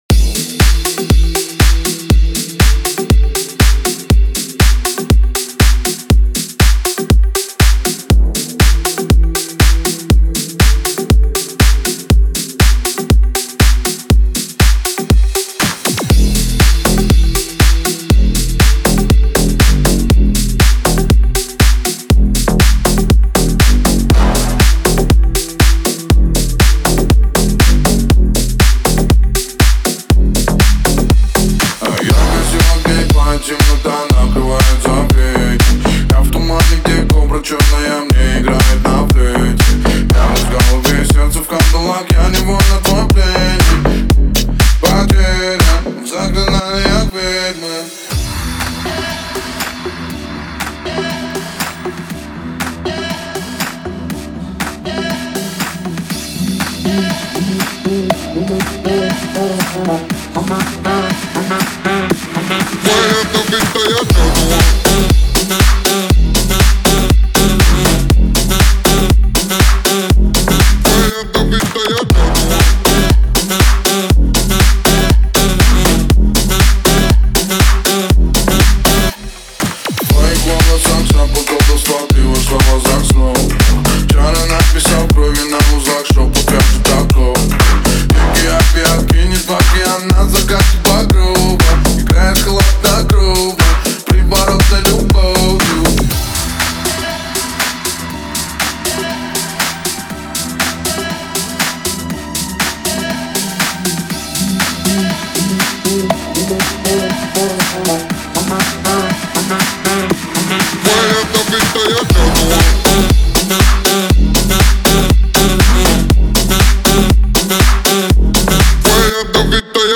звучание становится более динамичным и танцевальным